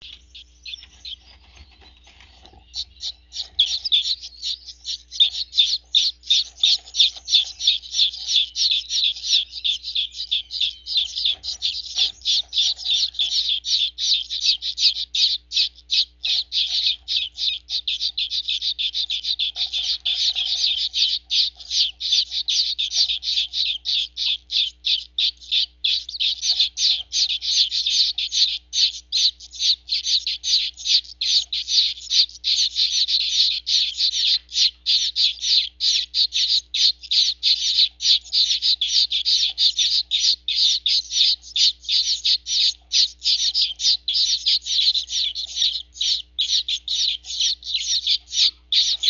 Audio clips were obtained from my personal breeding pairs.
Chicks Begging (.mp3, .7 MB)
begging.mp3